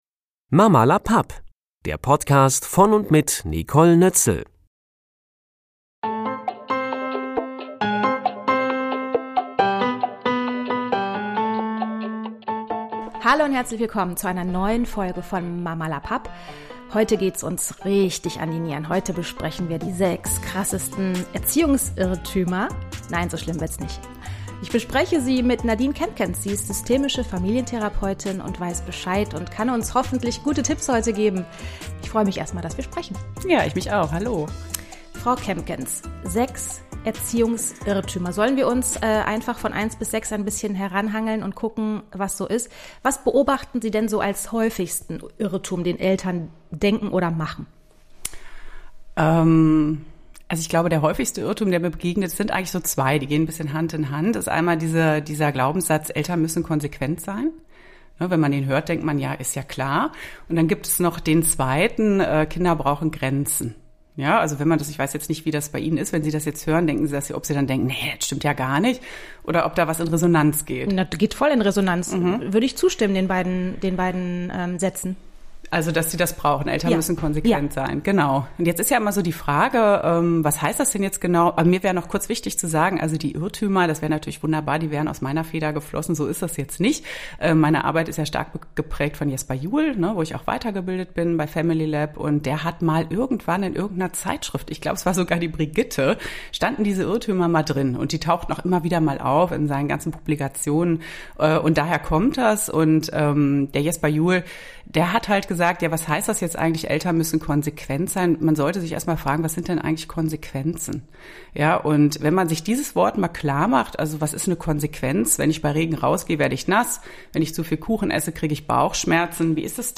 Bis zu diesem Interview.